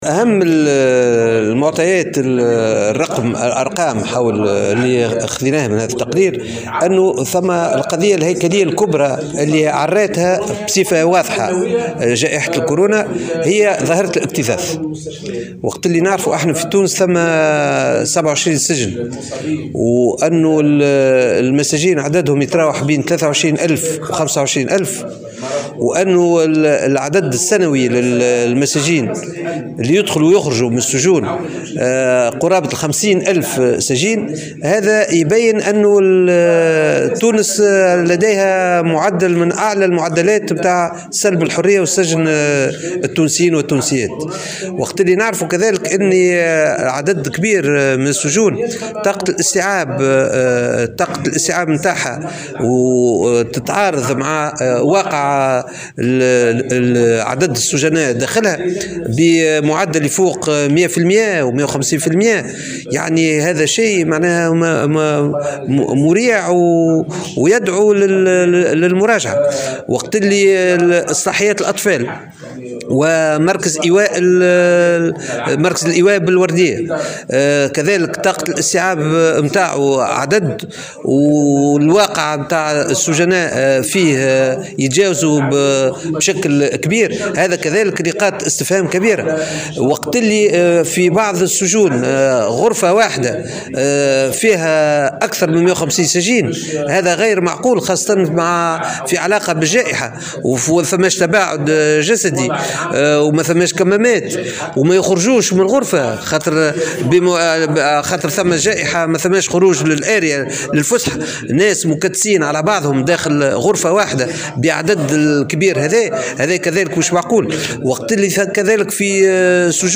قدمت المنظمة التونسية لمناهضة التعذيب في ندوة صحفية عقدتها اليوم الخميس بمقر نقابة الصحفيين بالعاصمة نتائج تقرير حول أوضاع السجون التونسية تحت وطأة جائحة كوفيد 19 .
تصريح لمراسل الجوهرة أف أم